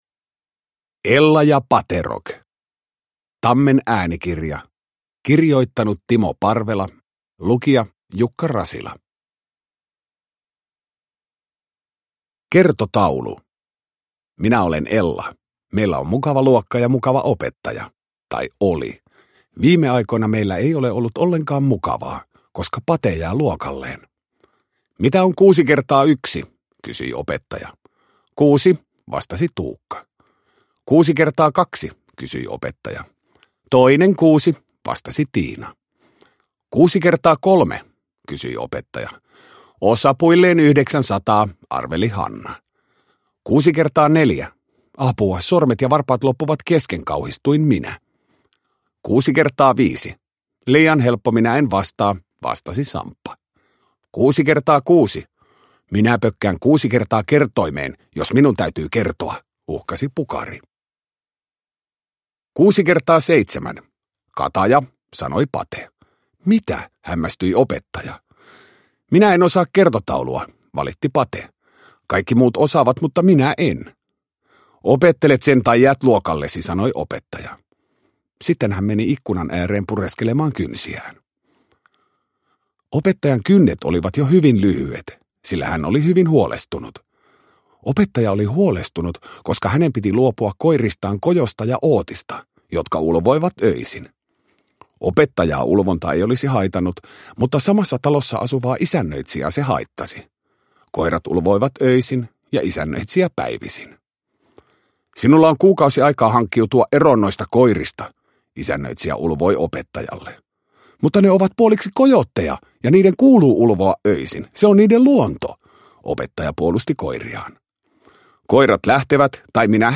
Ella ja Paterock – Ljudbok
Uppläsare: Jukka Rasila